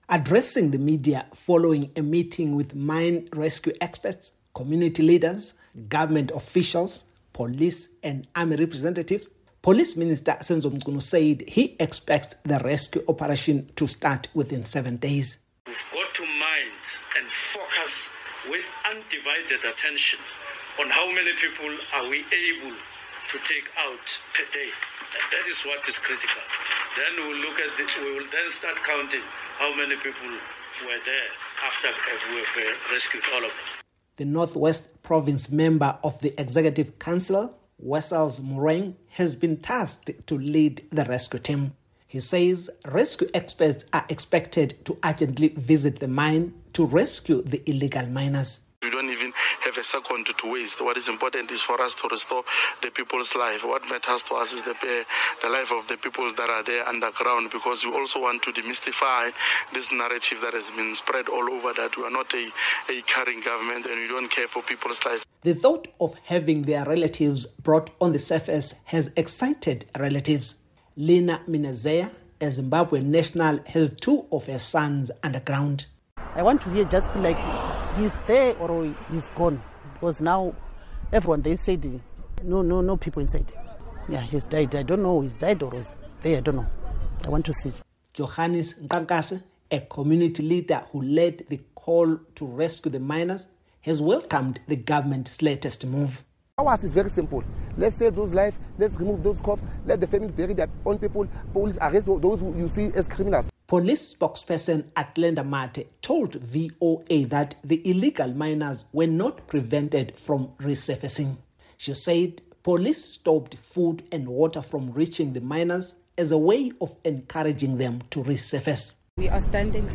reports from Johannesburg